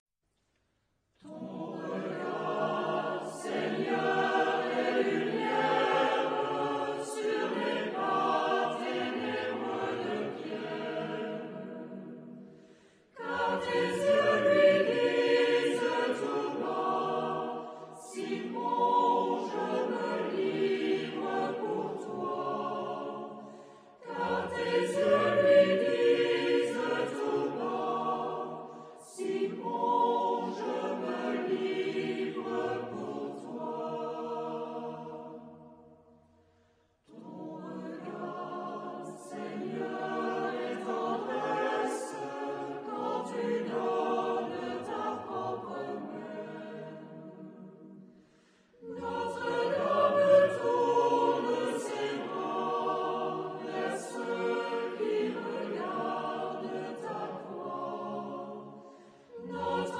Epoque: 20th century
Genre-Style-Form: Choir ; Sacred
Type of Choir: SATB  (4 mixed voices )
Tonality: E minor